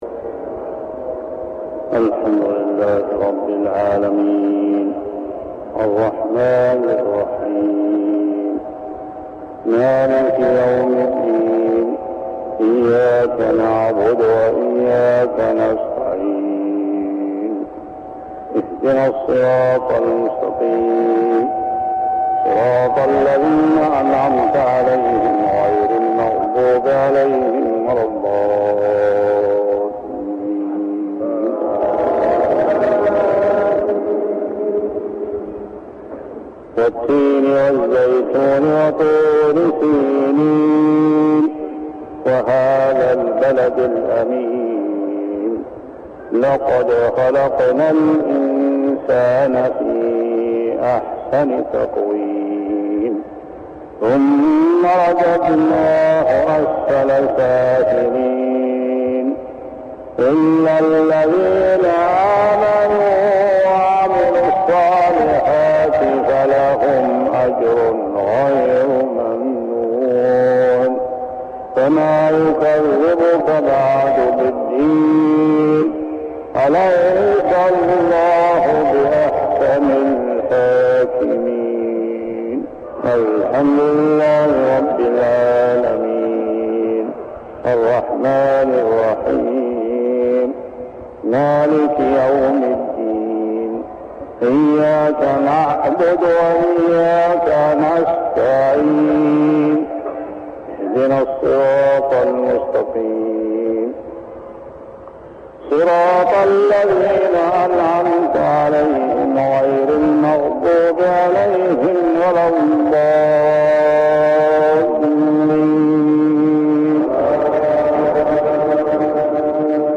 صلاة المغرب عام 1399هـ سورتي التين و قريش كاملة | maghrib prayer Surah At-Tin and Quraysh > 1399 🕋 > الفروض - تلاوات الحرمين